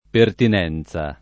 [ pertin $ n Z a ]